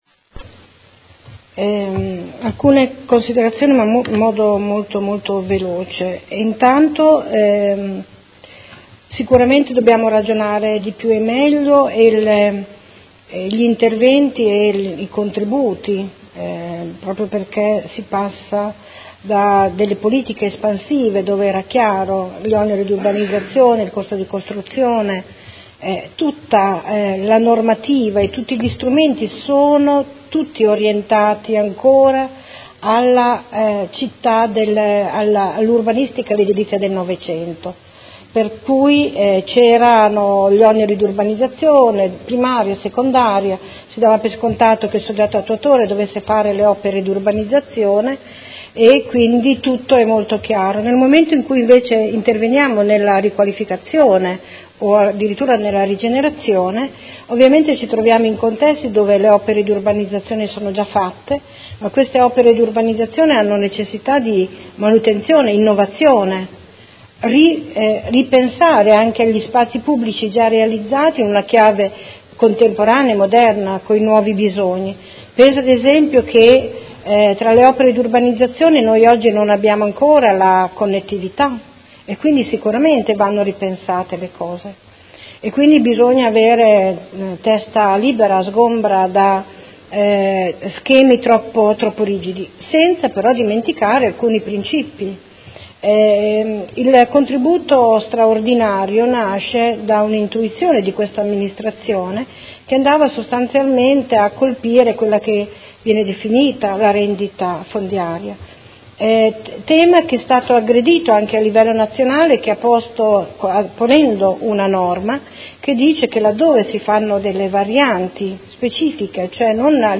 Seduta del 13/07/2017 Conclusione a dibattito: Delibera.